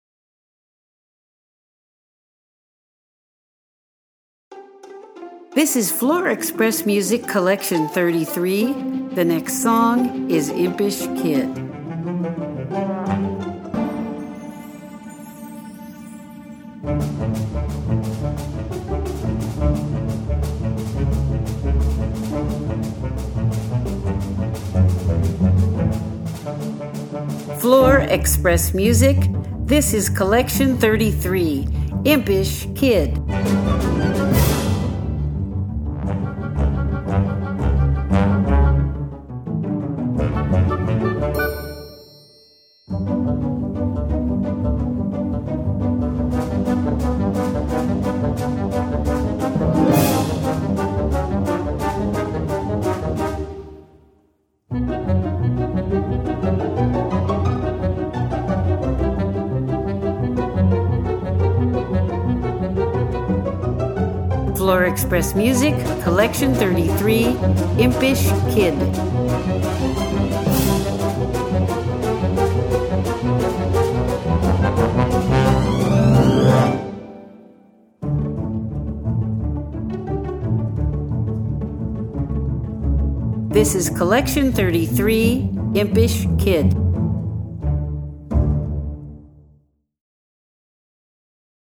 • Orchestration
• Cartoon